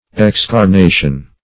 Search Result for " excarnation" : The Collaborative International Dictionary of English v.0.48: Excarnation \Ex`car*na"tion\, n. The act of depriving or divesting of flesh; excarnification; -- opposed to incarnation .